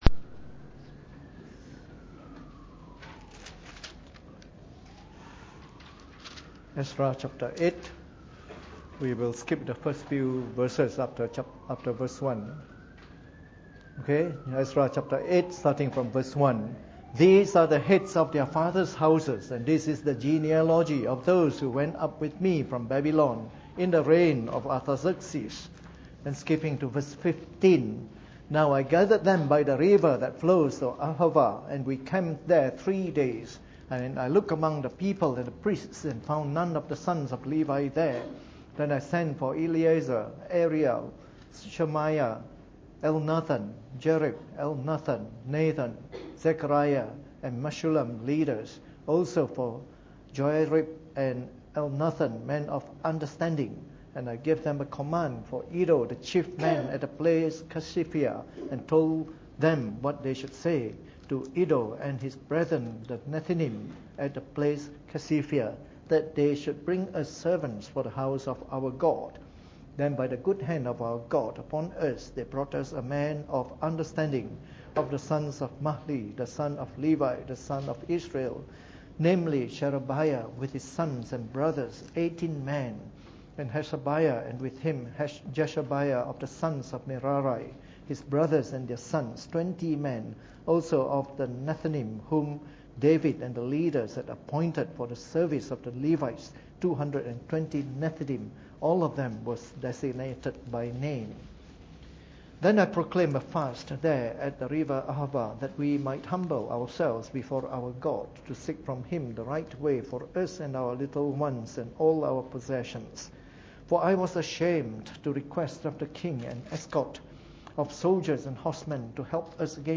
Preached on the 12th of March 2014 during the Bible Study, from our series of talks on the Book of Ezra.